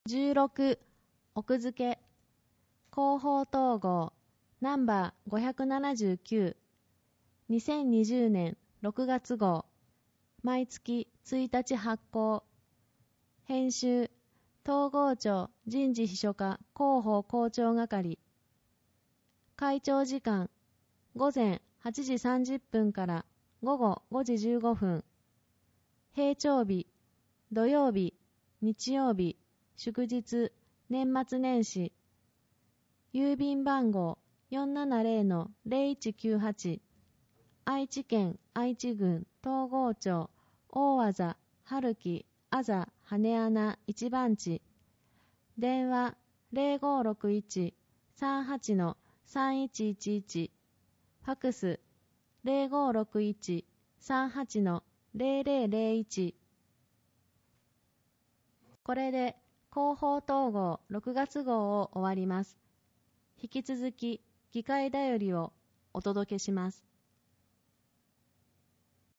広報とうごう音訳版（2020年6月号）